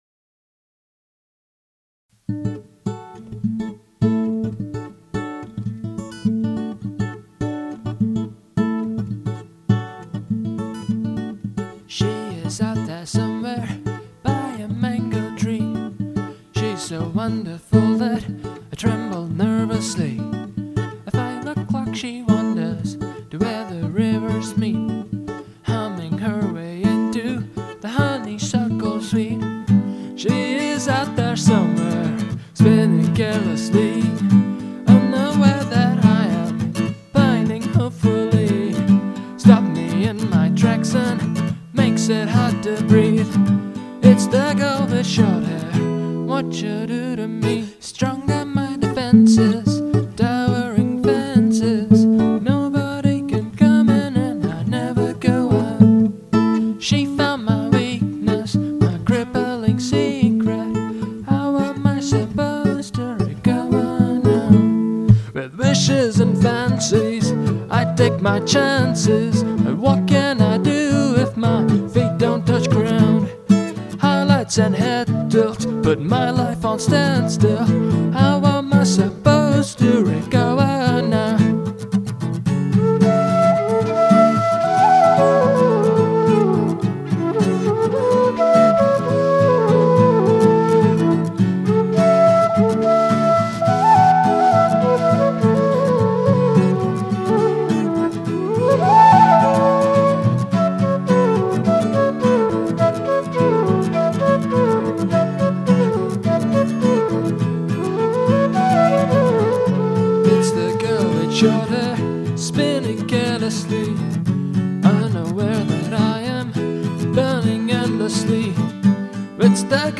I'm happy with the flute solo's trumpet like opening bit and I love the completely insane 5 part harmony on "be"